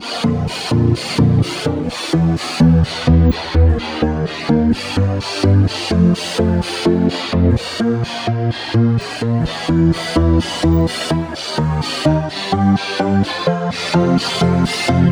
Track 10 - Arp Syncopation 03.wav